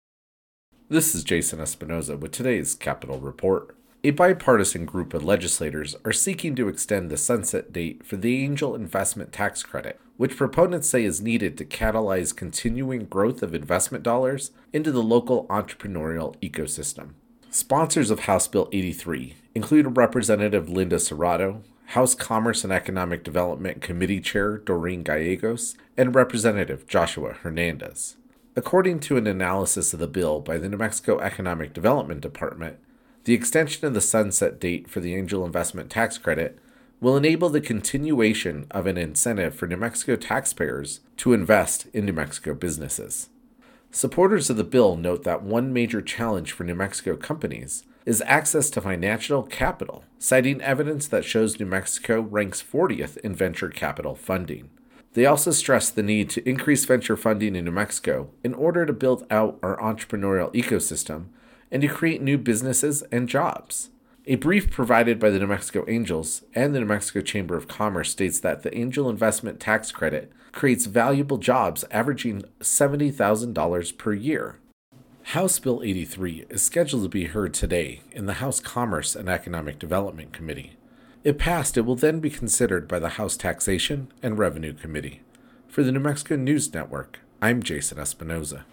capitol reports